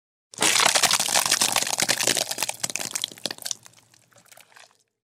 Звуки трещины
На этой странице собраны разнообразные звуки трещин — от ломающегося льда до скрипа дерева и раскалывающихся материалов.